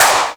VEC3 Claps 098.wav